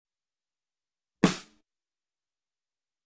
Descarga de Sonidos mp3 Gratis: tambor 26.